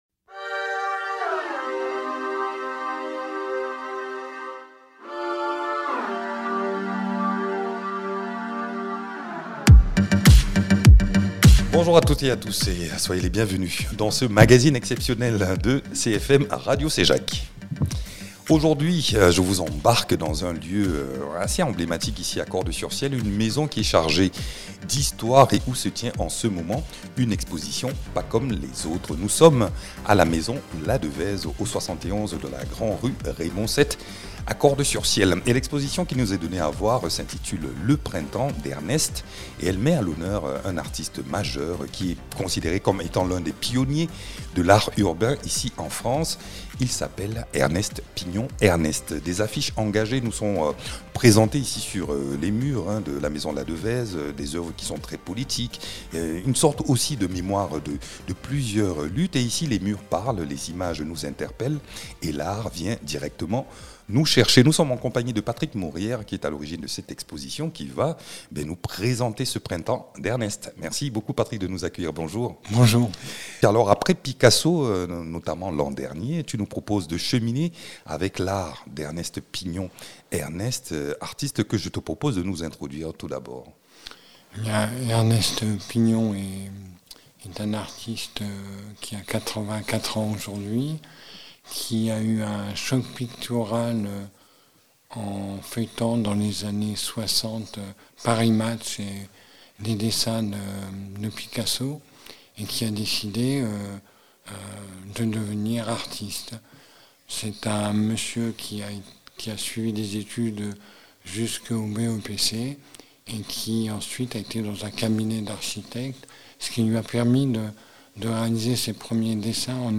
En immersion à la Maison Ladeveze de Cordes-sur-Ciel pour découvrir l’exposition Le Printemps d’Ernest qui est à voir jusqu’au 10 juin. Ici nous plongeons dans l’univers d’Ernest Pignon Ernest, pionnier de l’art urbain, à travers une sélection d’affiches engagées, entre mémoire, luttes et poésie.